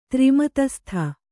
♪ tri matastha